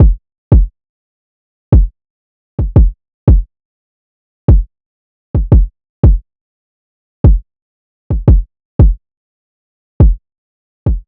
Kick - bird (edit).wav